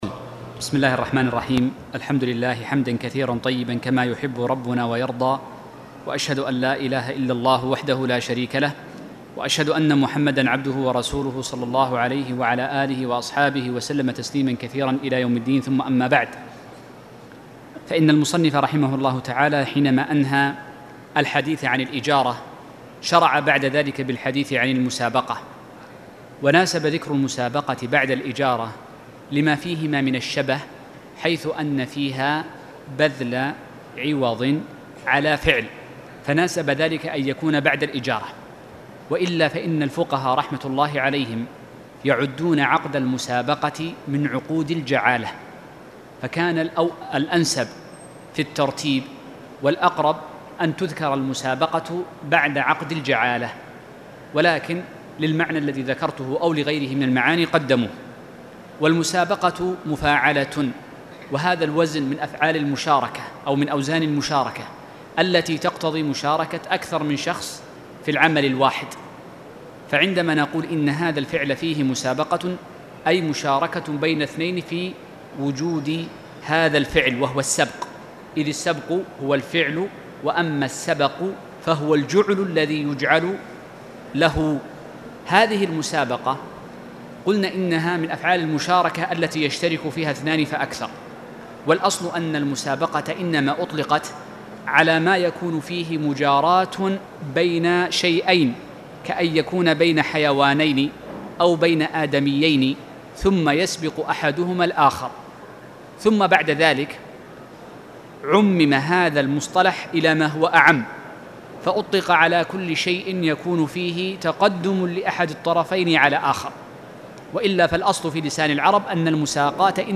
تاريخ النشر ٧ رجب ١٤٣٨ هـ المكان: المسجد الحرام الشيخ